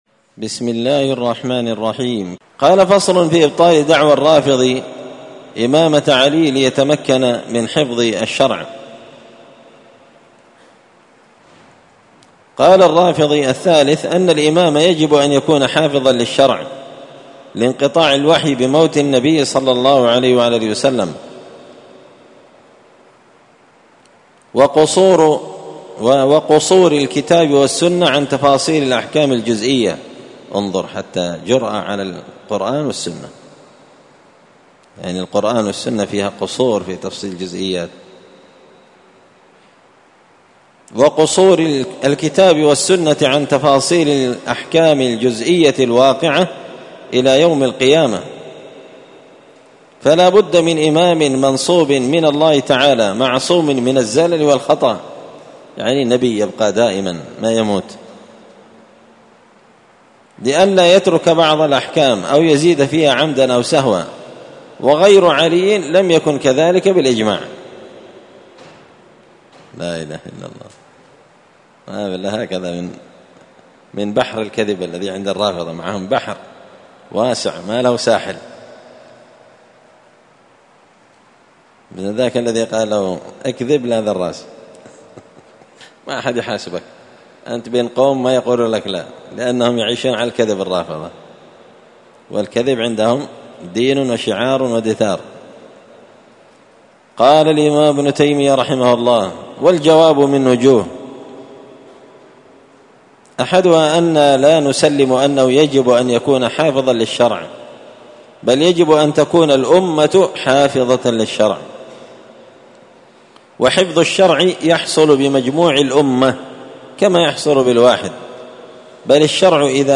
مسجد الفرقان قشن_المهرة_اليمن
146الدرس-السادس-والأربعون-بعد-المائة-من-مختصر-منهاج-السنة-فصل-في-إبطال-دعوى-الرافضي-إمامة-علي-ليتمكن-من-حفظ-الشرع.mp3